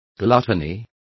Complete with pronunciation of the translation of gluttony.